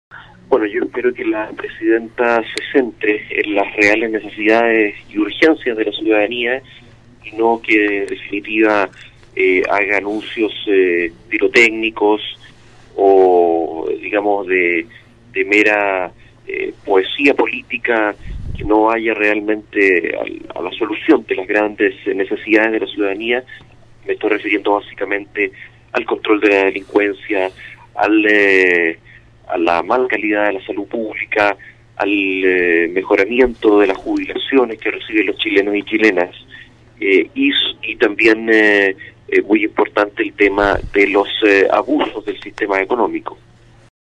Por su parte, el Diputado Independiente por el Distrito 11, Gaspar Rivas,  pidió una cuenta pública enfocada en la salud y la seguridad de la ciudadanía.